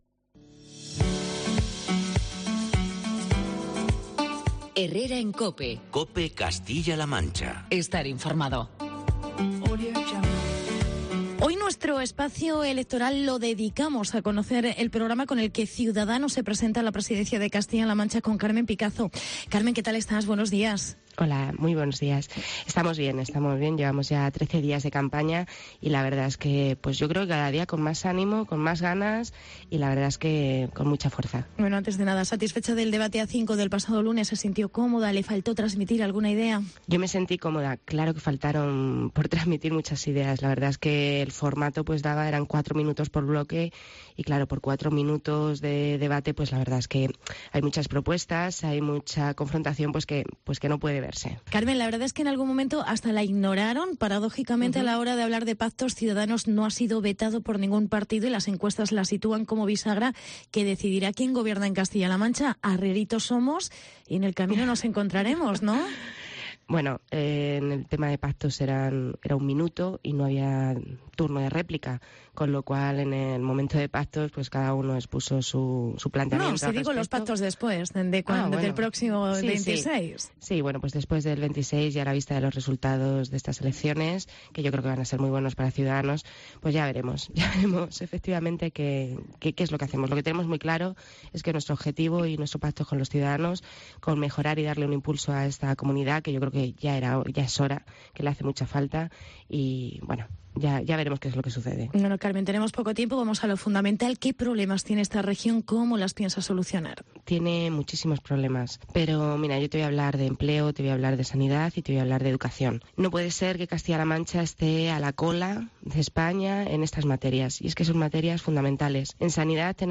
Entrevista con Carmen Picazo. Candidata Ciudadanos Junta de CLM